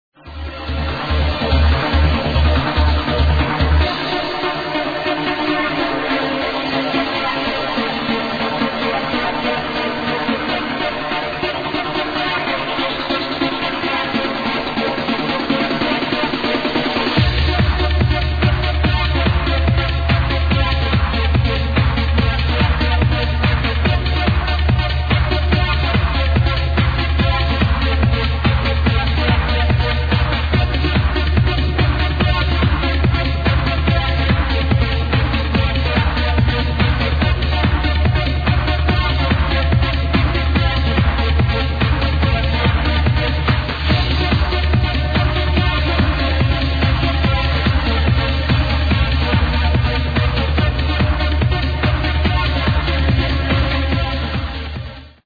Dancing Dude ANother Amazing Trancer...Sample Inside